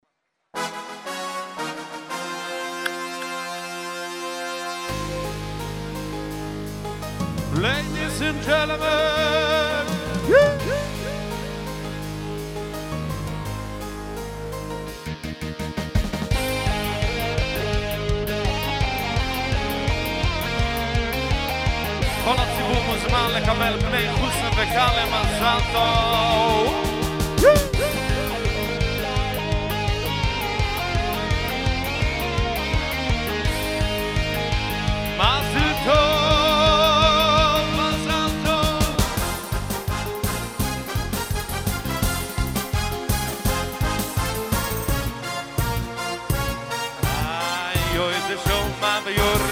שתי כניסות חתן ביום אחד!!🥂🥂
אולי תנסה לשנות את הכלי שמנגן את הסולו, וכן להוסיף למקצב עצמו, לתופים, איזה תפקיד שיגביר את תחושת הדרמה כי כרגע זה כאילו זורם כזה.